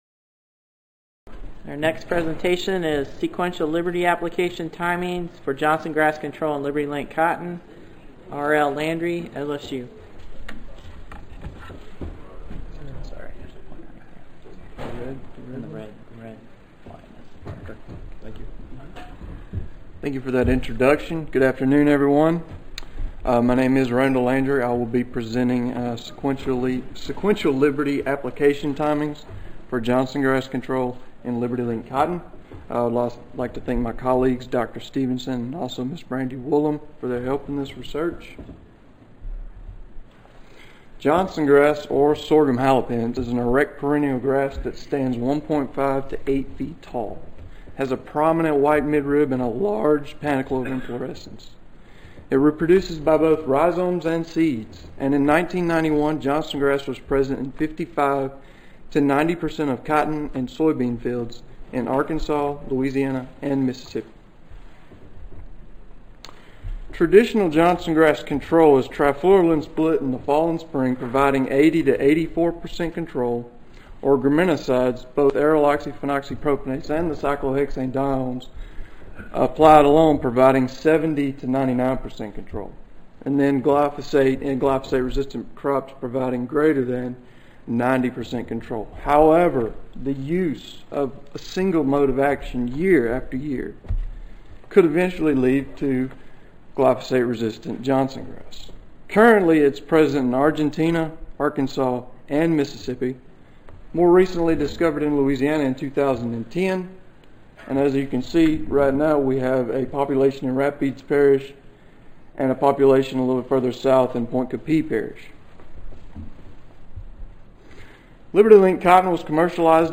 Session: Cotton Weed Science Research - Graduate Student Paper Session I (2014 Beltwide Cotton Conferences (January 6-8, 2014))
Recorded Presentation